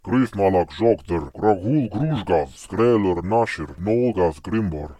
It incorporates a range of vocalizations, alternating between normal voice production and deep, guttural growls, reminiscent of the Fomorians' intimidating presence.